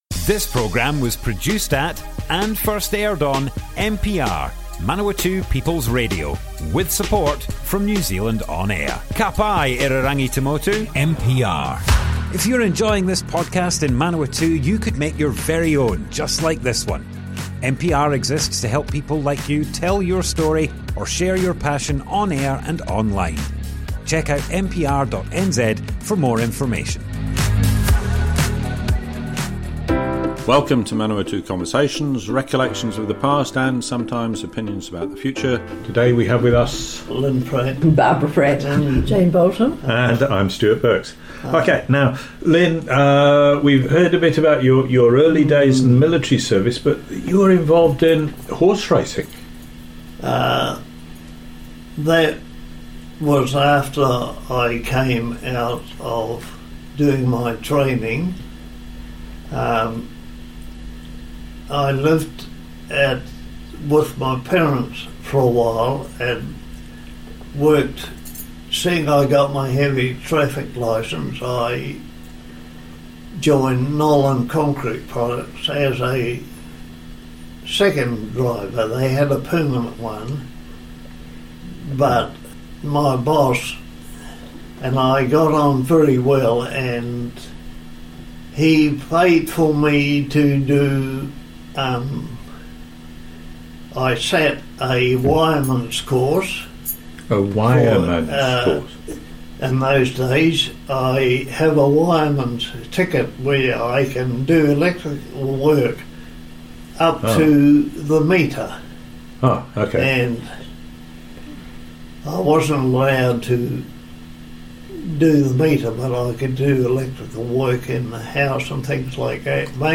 Manawatu Conversations More Info → Description Broadcast on Manawatu People's Radio, 13th June 2023.